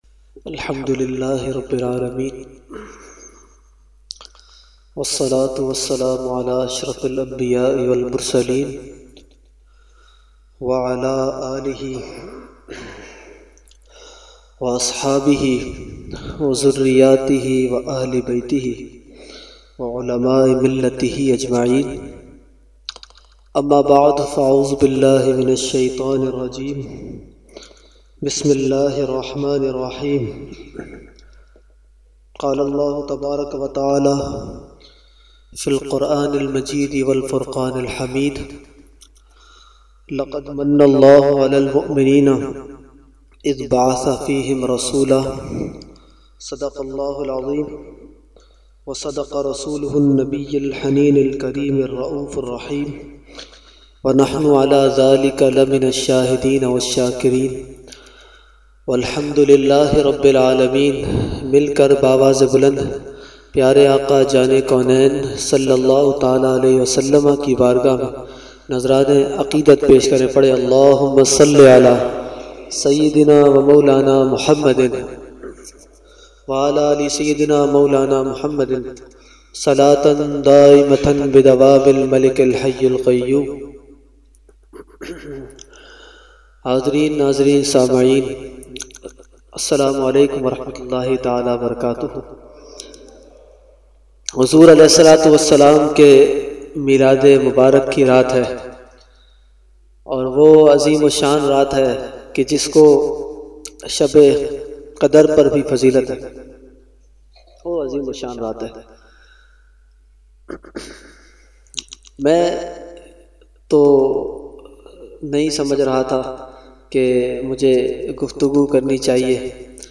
Category : Speech | Language : UrduEvent : Jashne Subah Baharan 2019